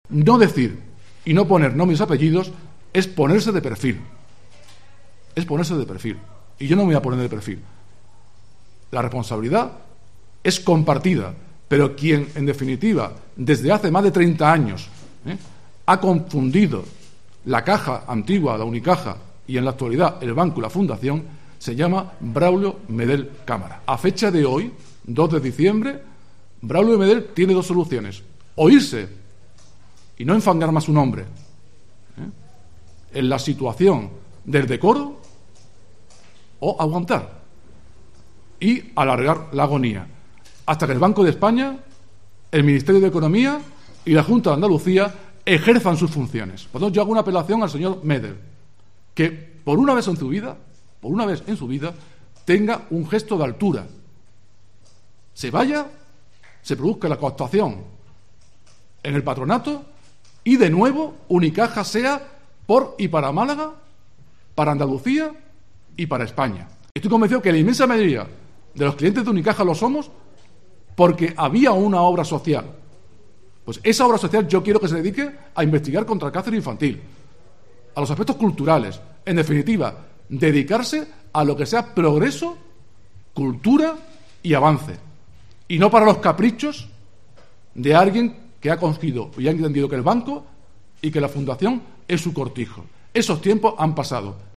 El representante de la plataforma 'Salvemos Unicaja´, el profesor en Derecho y exportavoz municipal de Izquierda Unida, Pedro Moreno Brenes, ha protagonizado un acto público donde ha señalado al actual presidente de la Fundación Unicaja, Braulio Medel, como principal responsable de la situación que está atravesando la entidad.